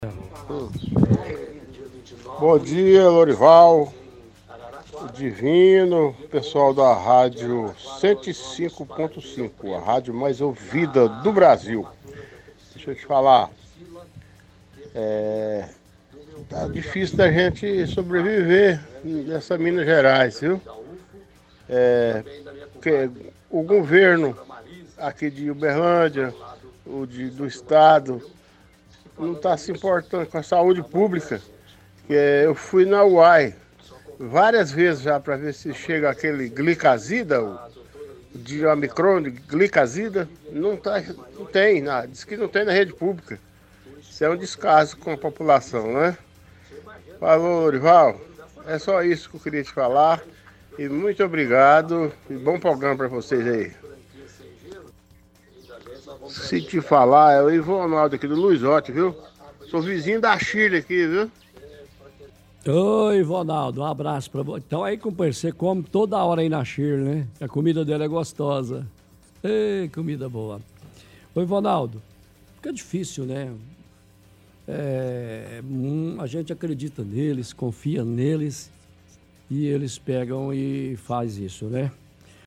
– Ouvinte do bairro Luizote reclama do governo do Estado e do município.